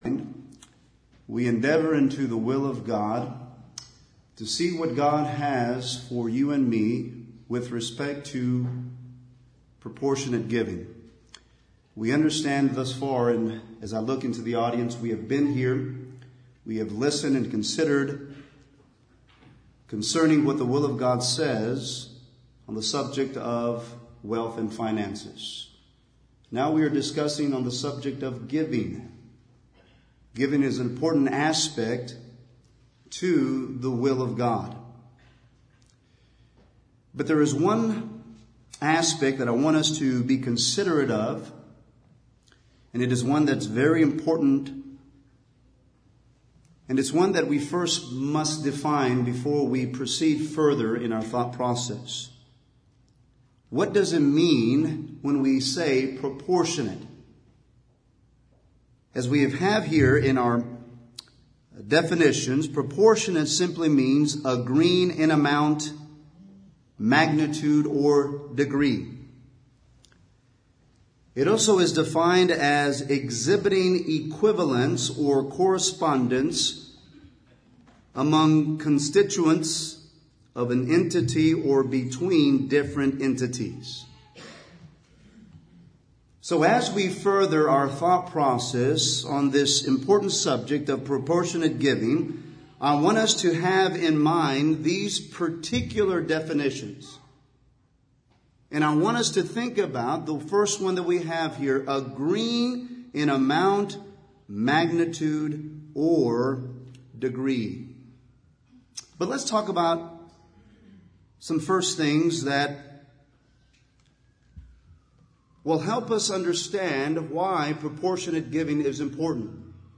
Event: 26th Annual Shenandoah Lectures